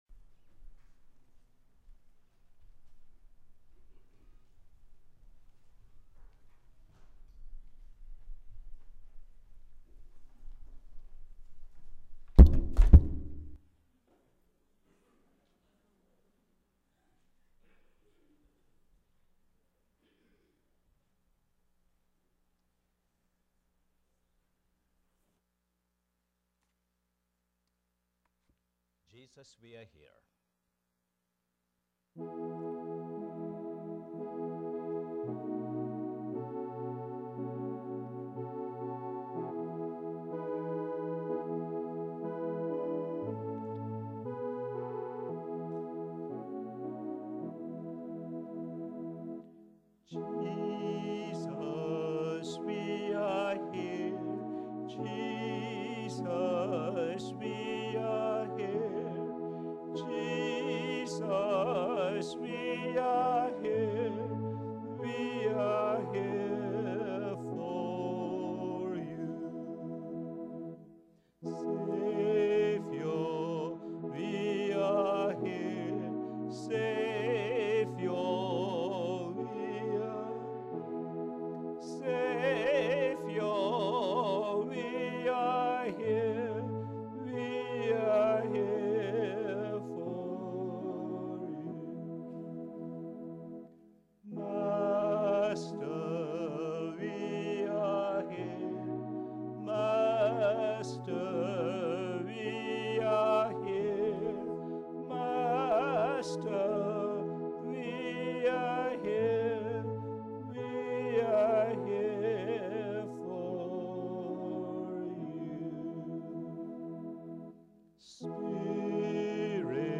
Posted in Sermons on 10.